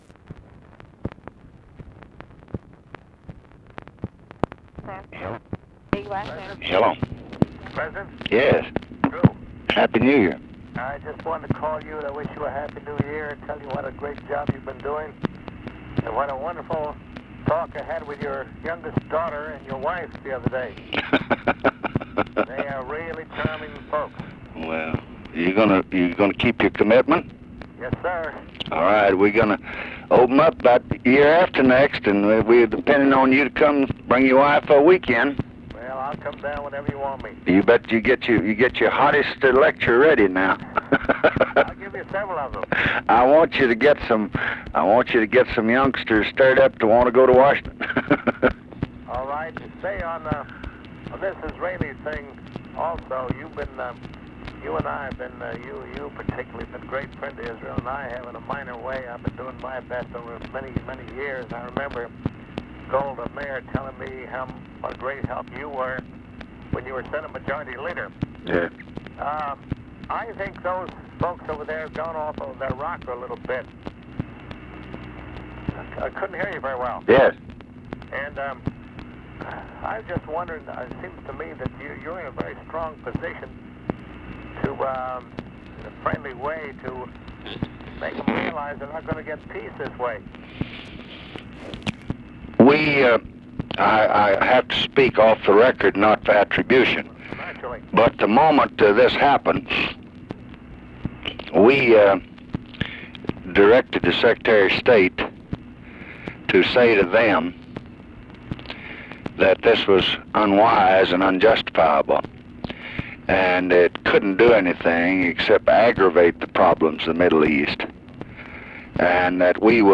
Conversation with DREW PEARSON, December 30, 1968
Secret White House Tapes